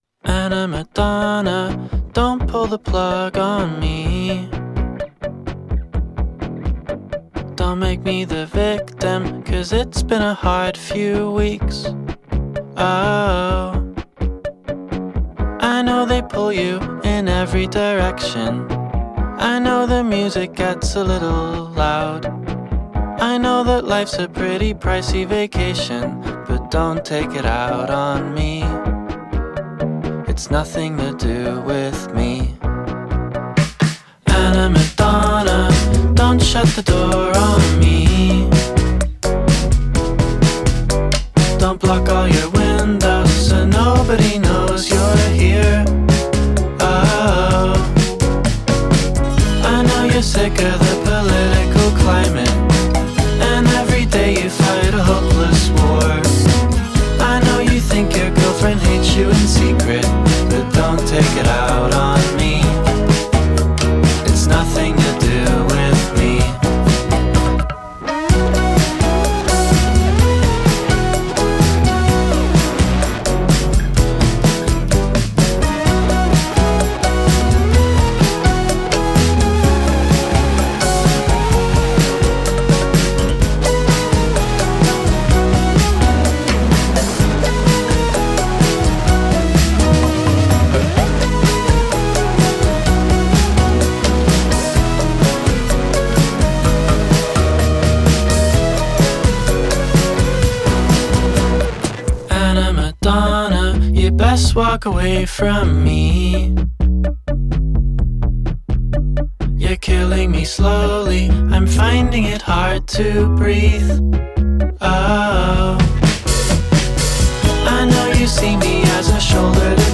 Perky yet melancholy
Crisply mixed and brightly melodic